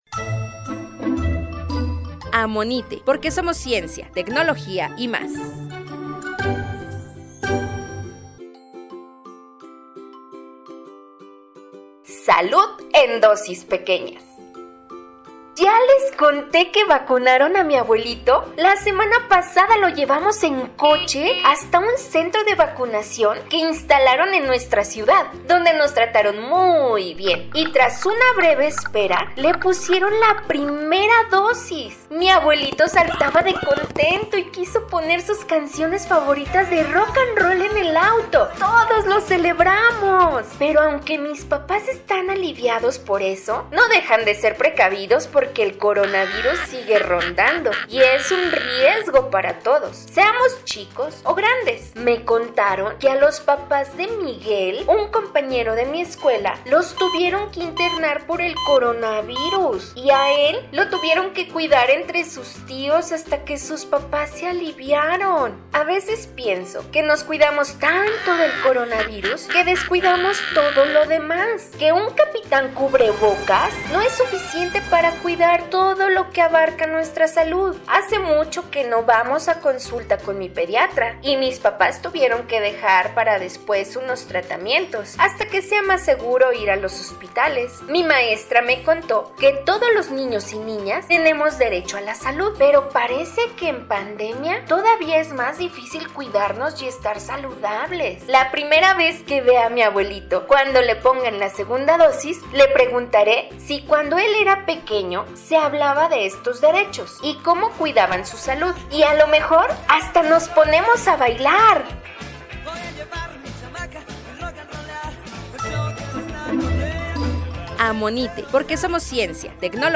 Tres audiocuentos para reflexionar en torno a los derechos de los niños y las niñas en el contexto de la pandemia por Covid-19.
Locución y audio